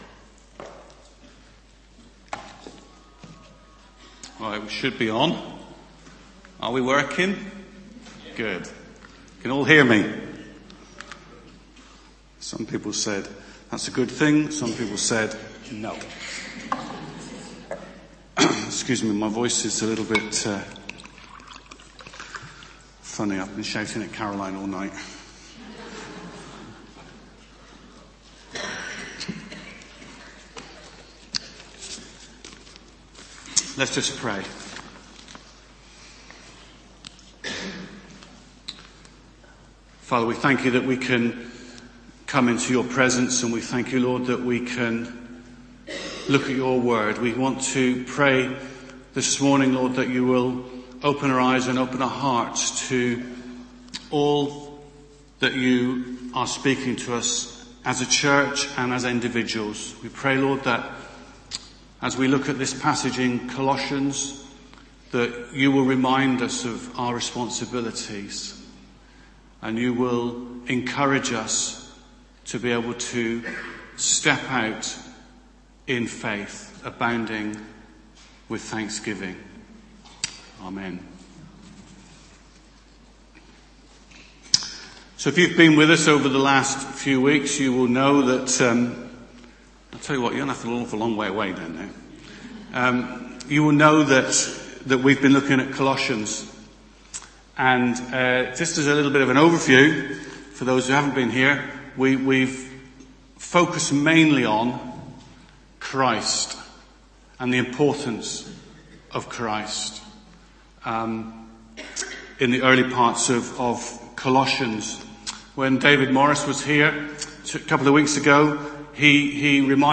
Preached at South Parade Baptist Church, Leeds